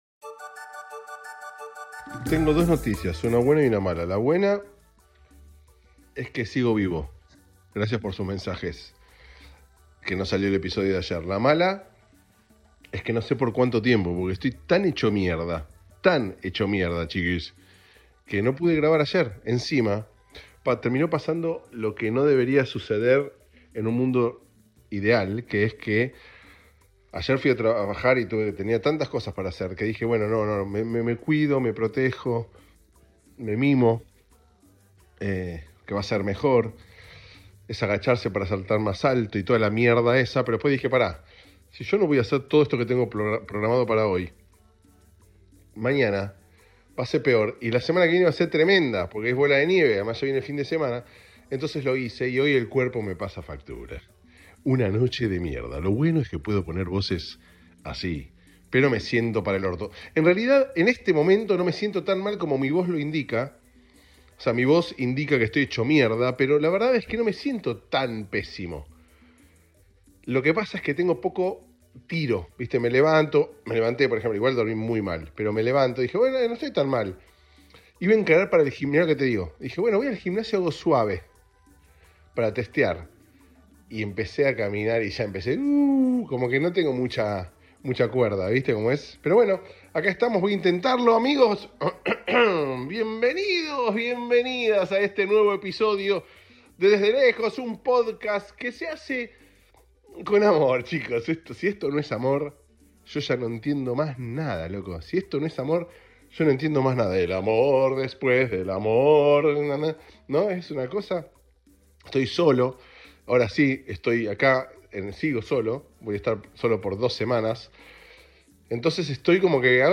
En la lucha, en la ducha, en la dicha, con la ficha bien puesta... así se grabó el episodio de hoy.